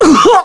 Kasel-Vox_Damage_kr_02.wav